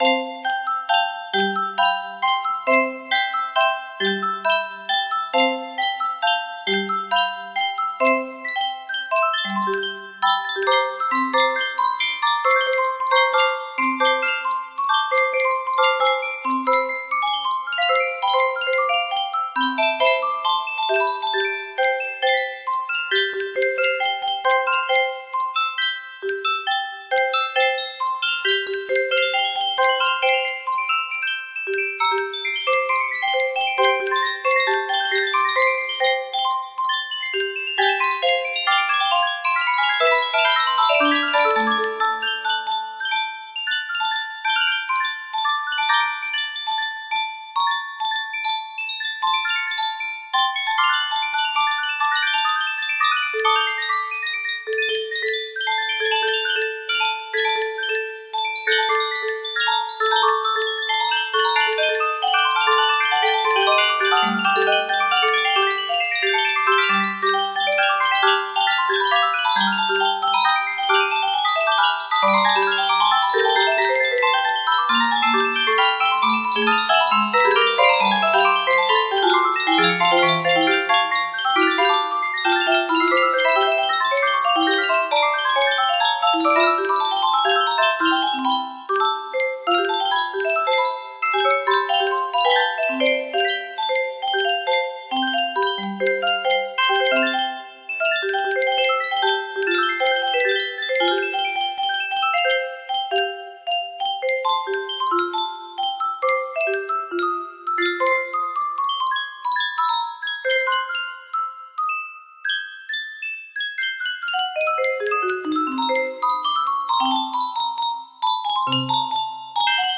À la manière de Borodine part 1 music box melody
Full range 60